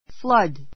flood A2 flʌ́d ふ ら ド 名詞 大水, 洪水 こうずい 動詞 （川などが） 氾濫 はんらん する; （川の氾濫・大雨などが） ～を水浸 びた しにする; 水浸しになる The river flooded the village.